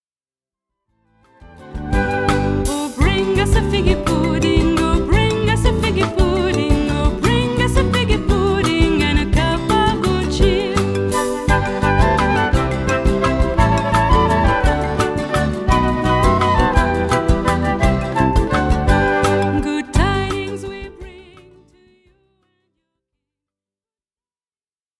Les grands classiques de Noël
voix cristalline